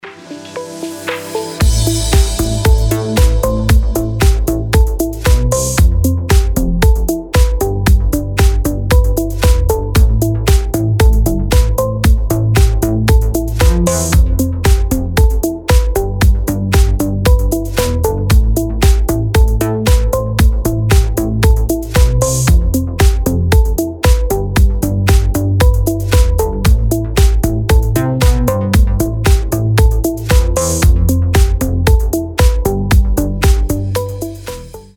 • Качество: 320, Stereo
deep house
без слов
красивая мелодия
nu disco
Indie Dance